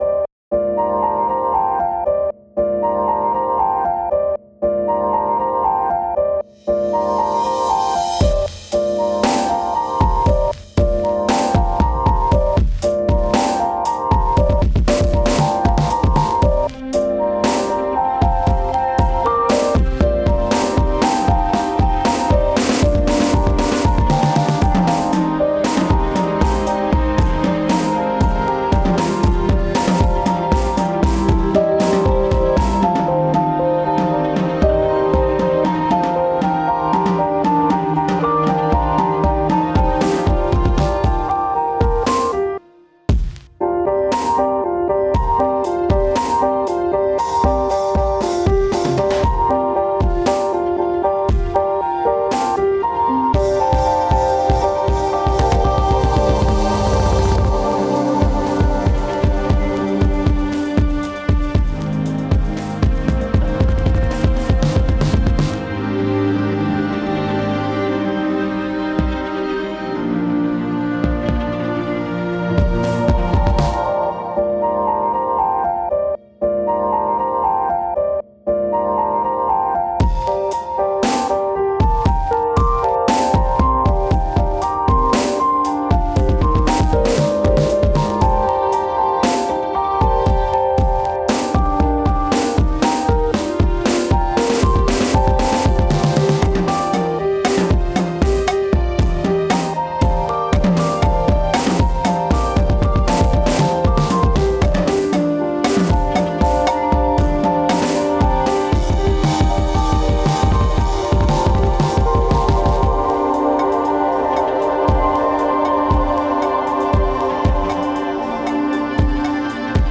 An original piano and instrumental track.